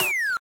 snd_hitcar_little.ogg